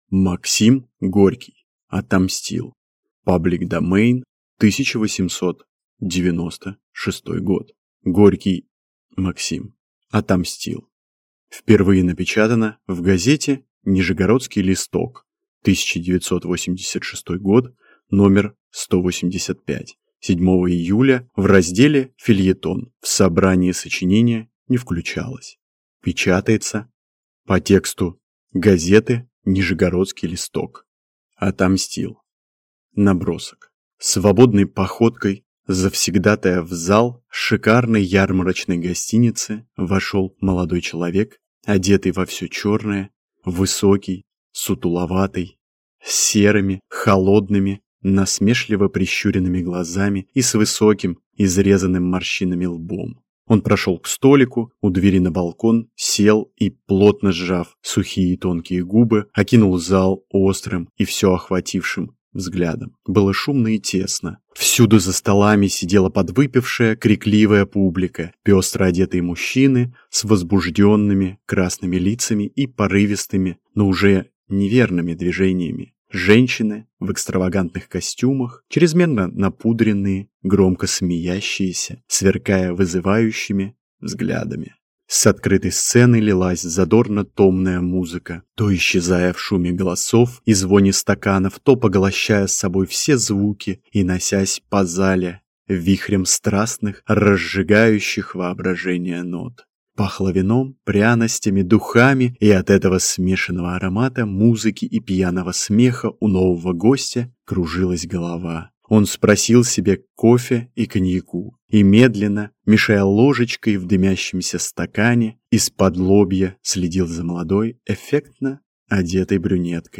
Аудиокнига Отомстил | Библиотека аудиокниг